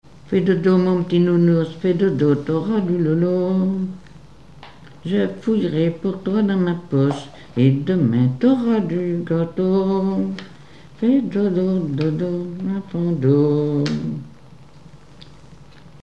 berceuse
Pièce musicale inédite